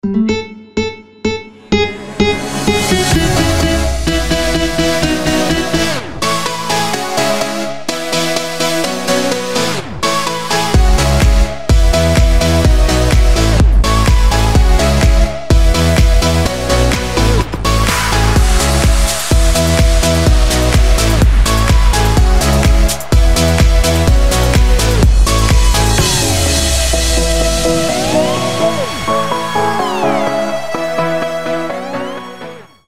• Качество: 320, Stereo
позитивные
громкие
EDM
без слов
progressive house